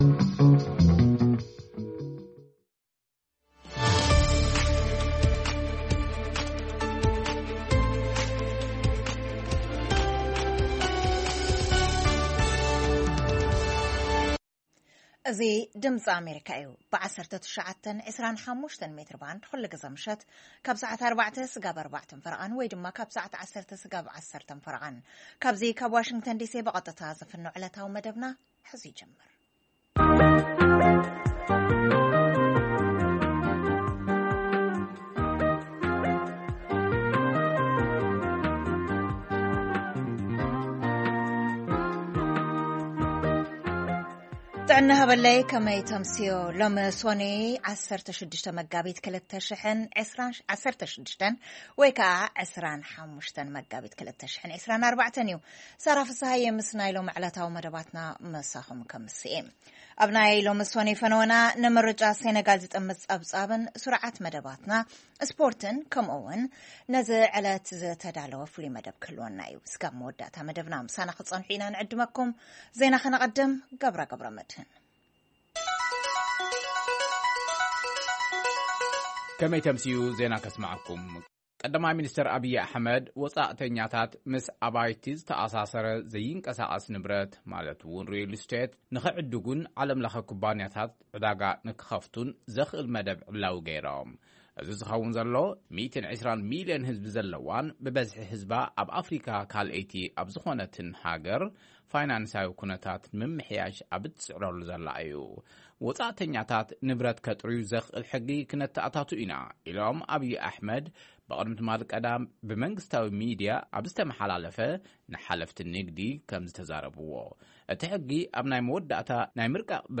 ፈነወ ድምጺ ኣመሪካ ቋንቋ ትግርኛ 25 መጋቢት 2024 ዕለታዊ ዜና - መግለጺ ጊዝያዊ ምምሕዳር ክልል ትግራይ: ቀ/ሚ ኢትዮጵያ ንዜጋታት ወጻኢ ሃገር ዘይንቀሳቀስ ንብረት ክገዝኡ ዘኽእል መደብ ምውጽኡ ገሊጾም: ኣብ ሩስያ ዝተፈጸመ መጥቃዕቲ ስዒቡ 4 ሰባት ኣብ ቐይዲ ምእታዎም ተገሊጹ ዜና ስፖርት ኣብ ኢትዮጵያ ዝረኣ ዘሎ ምቁራጽ ሕትመት ዝምልከት መደብ የጠቓልል